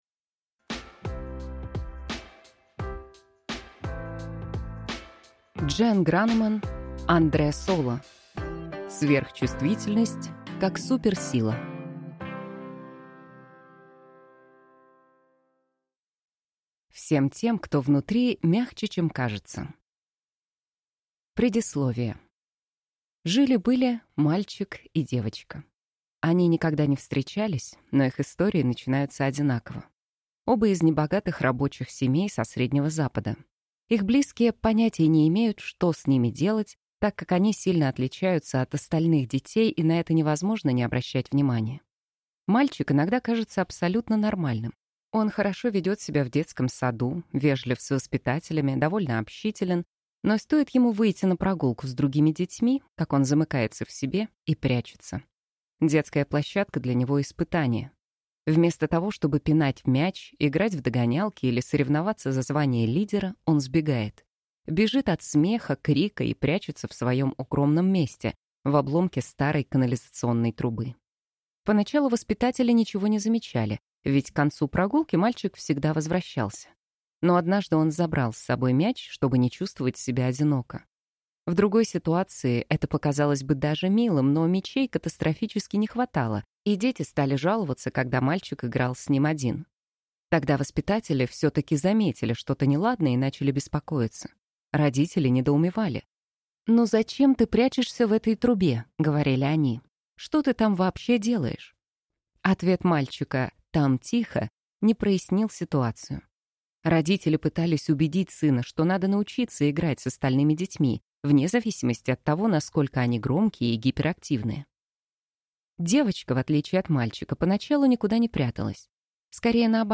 Аудиокнига Сверхчувствительность как суперсила | Библиотека аудиокниг